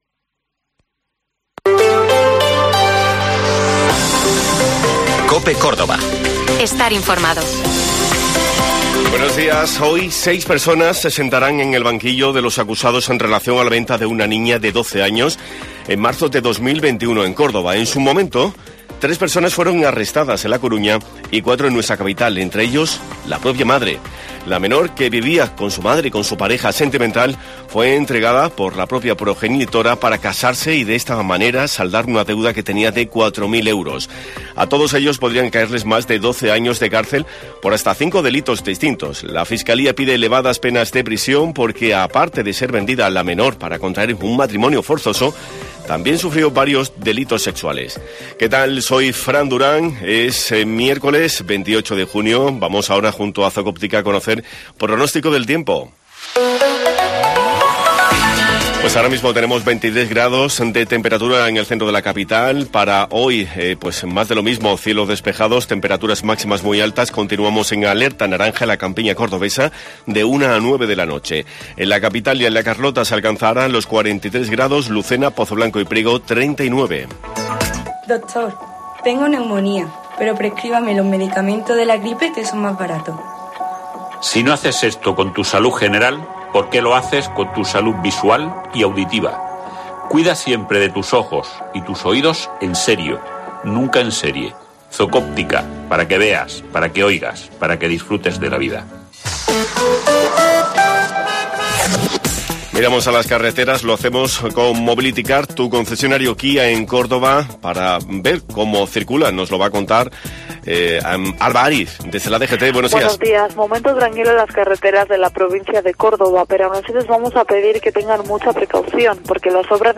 Informativo Herrera en COPE Córdoba